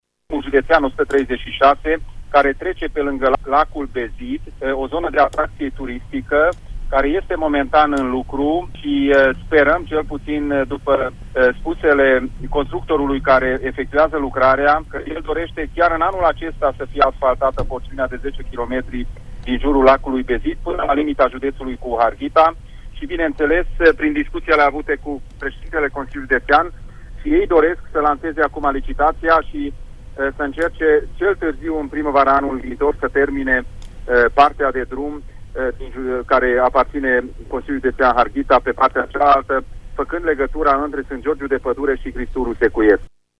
Constructorul lucrării dorește ca cei 10 km de drum din jurul Lacului Bezid, care aparțin de județul Mureș, să fie asfaltați până la sfârșitul acestui an, a declarat în emisiunea Pulsul Zilei de la RTM, președintele CJ Mureș, Peter Ferenc: